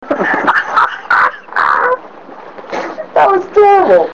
Laugh 4